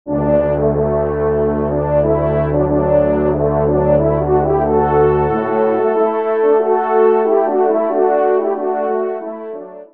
20 sonneries pour Cors et Trompes de chasse